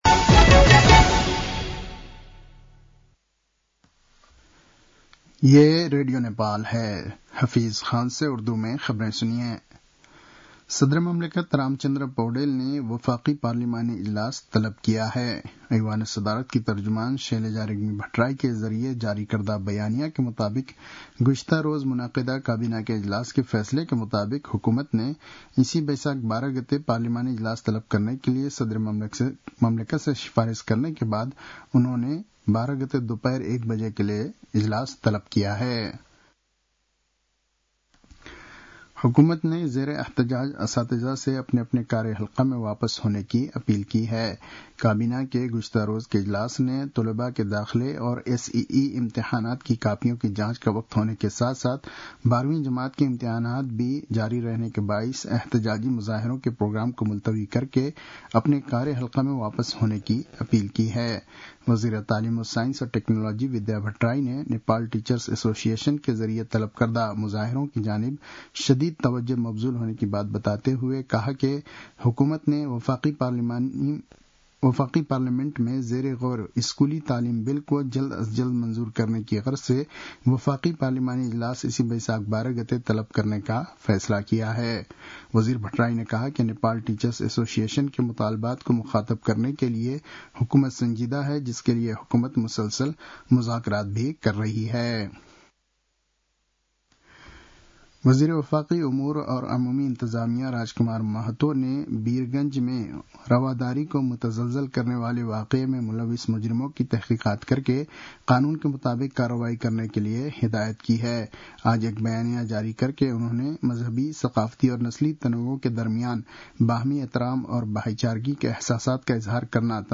उर्दु भाषामा समाचार : ३ वैशाख , २०८२
Urdu-news.mp3